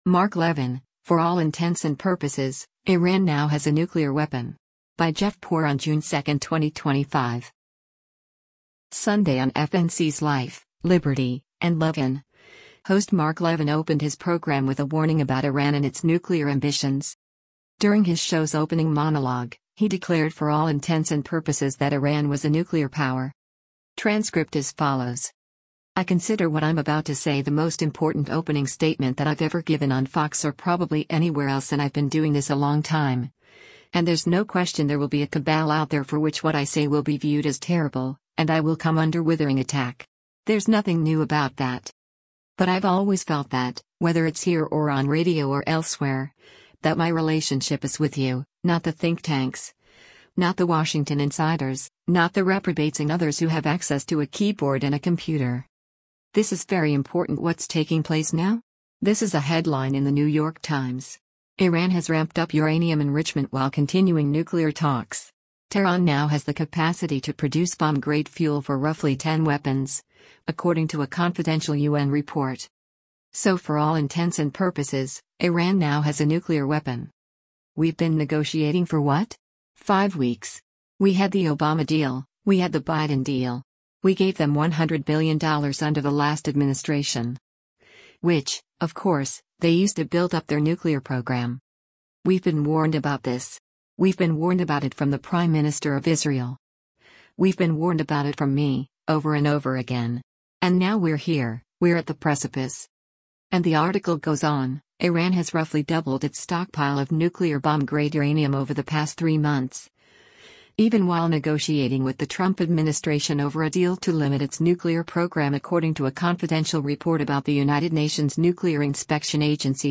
Sunday on FNC’s “Life, Liberty & Levin,” host Mark Levin opened his program with a warning about Iran and its nuclear ambitions.